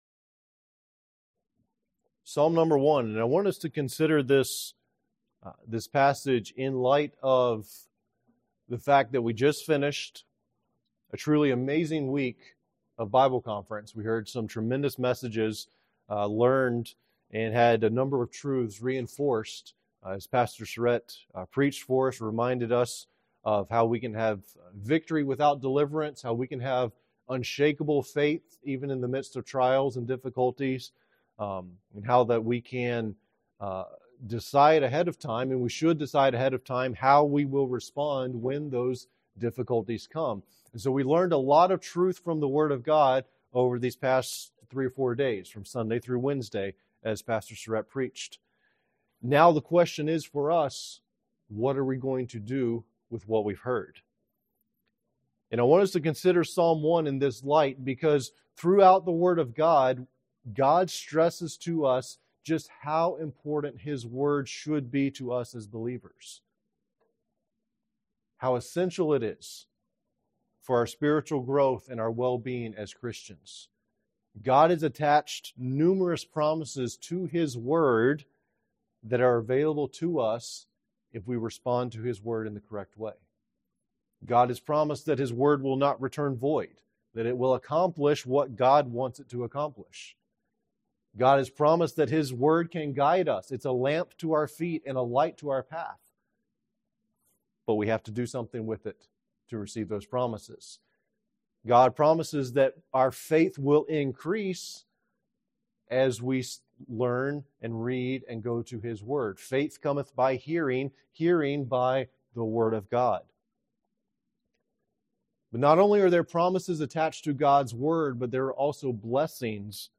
Topical